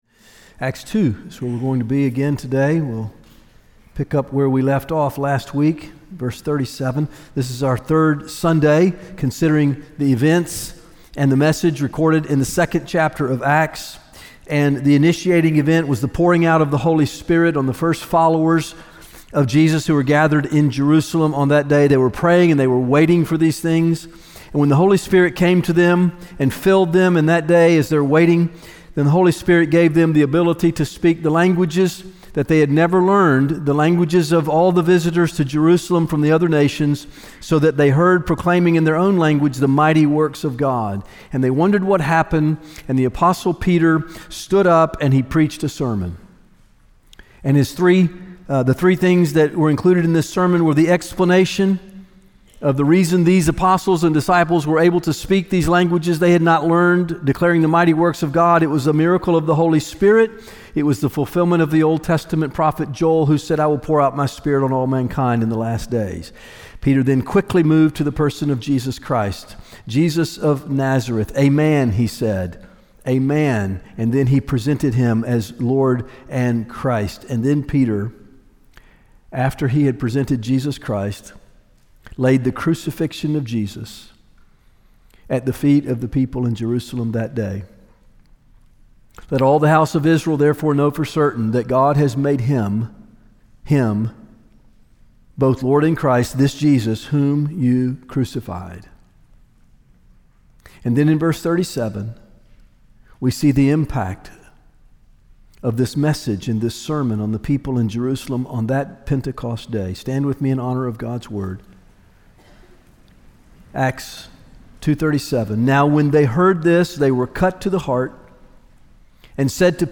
One of the ways that we pursue this mission is by gathering each Sunday for corporate worship, prayer, and biblical teaching.